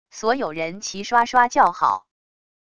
所有人齐刷刷叫好wav音频